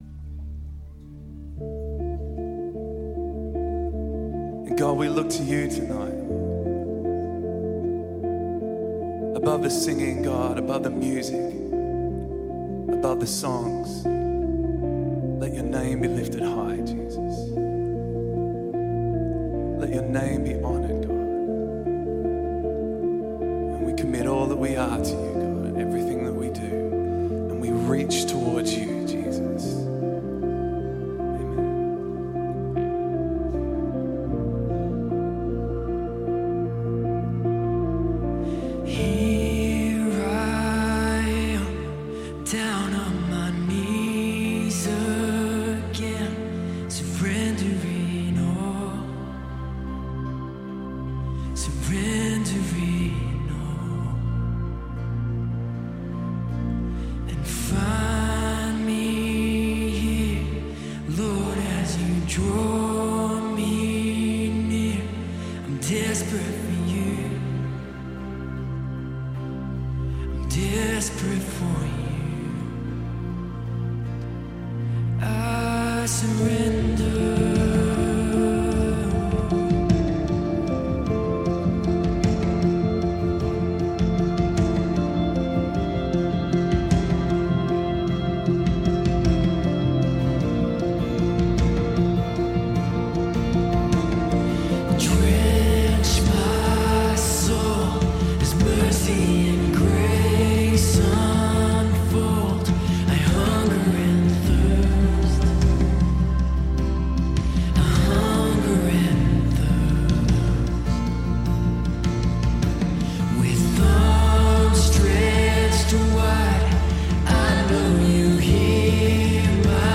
Australian Christian music praise & worship group